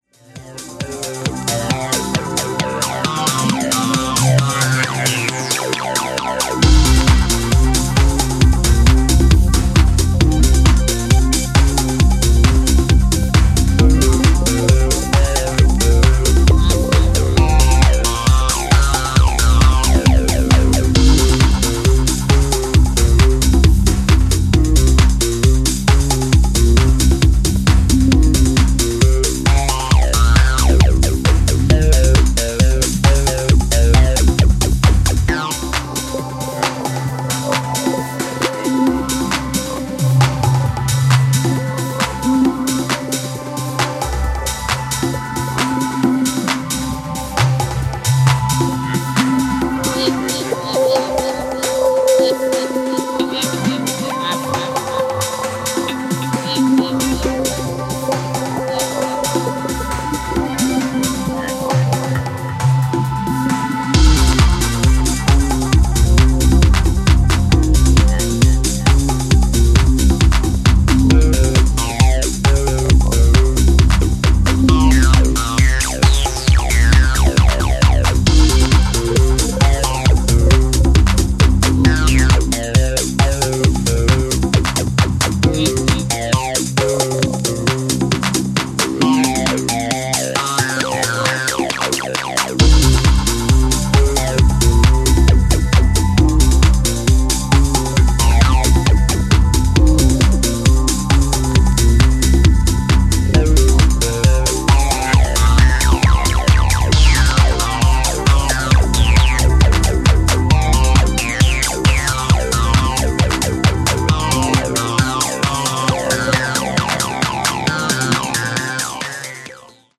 全編エレクトロ感覚もうっすらと感じさせるパンピン&ファンキーなテック・ハウスでこれはフロアをガツンと揺らせそう。